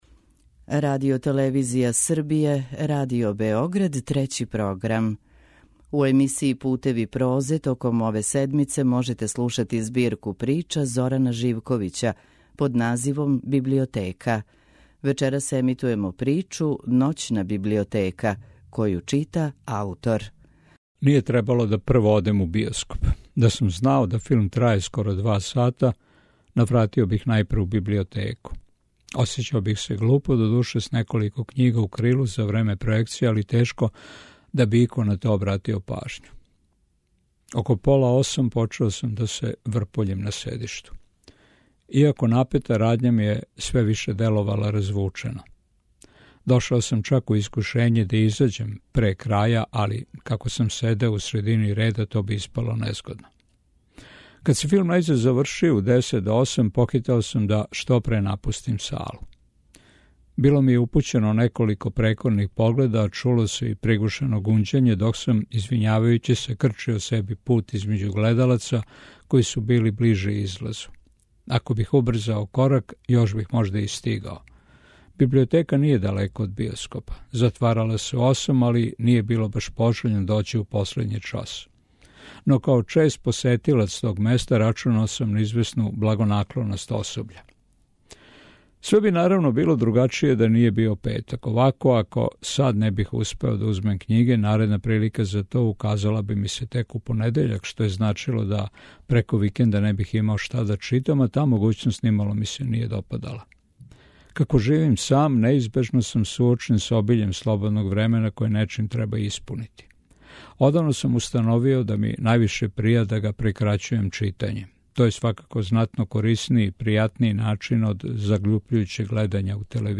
У емисији Путеви прозе, од понедељка, 3. до недеље, 9. октобра, можете слушати ново издање мозаичког романа Зорана Живковића „Библиотека” који ће читати аутор.